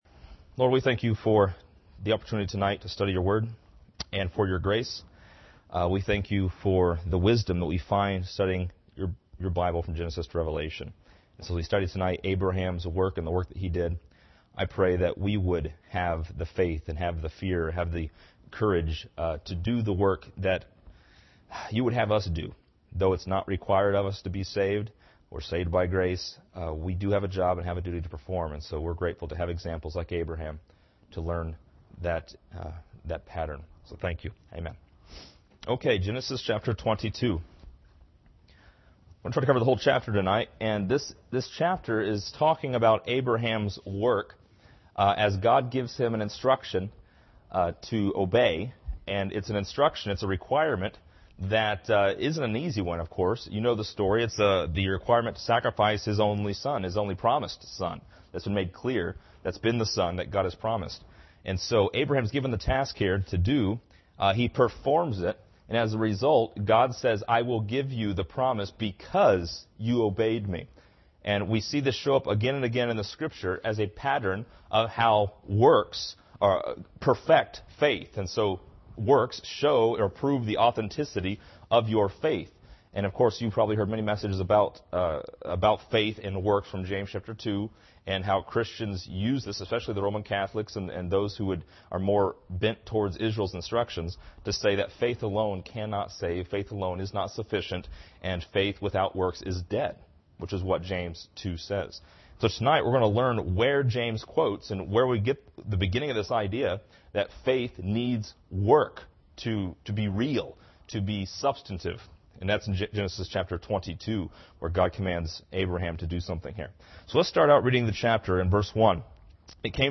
This lesson is part 32 in a verse by verse study through Genesis titled: Abraham’s Work.